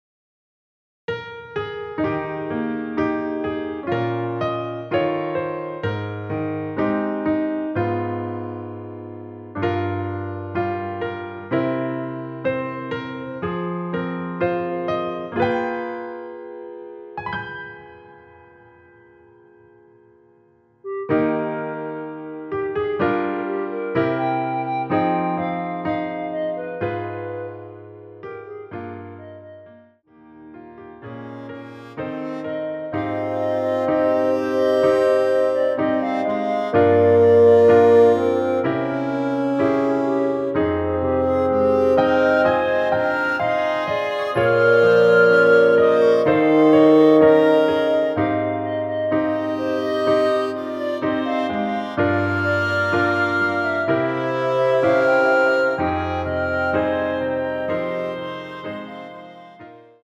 원키에서(+3)올린 멜로디 포함된 MR입니다.(미리듣기 확인)
Eb
앞부분30초, 뒷부분30초씩 편집해서 올려 드리고 있습니다.